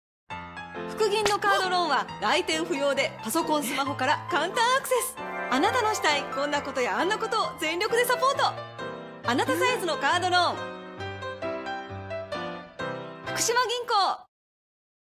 • ナレーター
サンプルボイス1